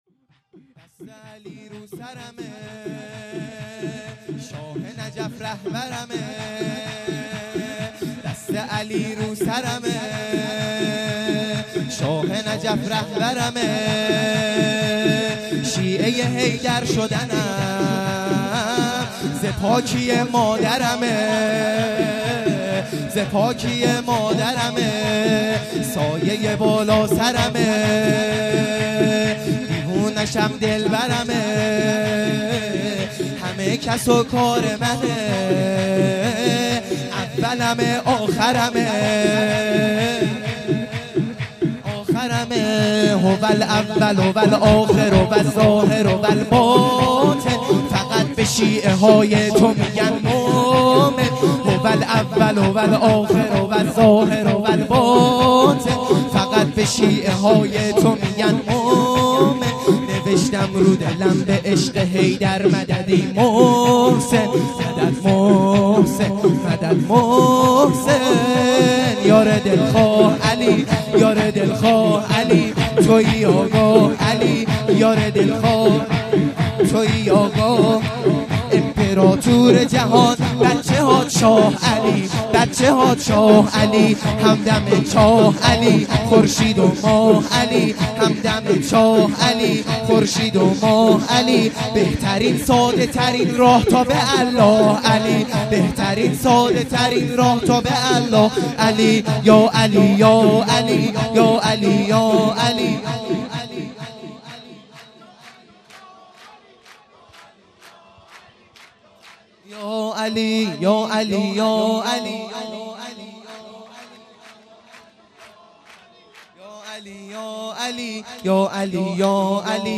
سرود | دست علی رو سرمه
میلاد شهزاده علی اکبرعلیه السلام